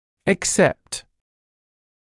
[ɪk’sept][ик’сэпт]за исключением, кроме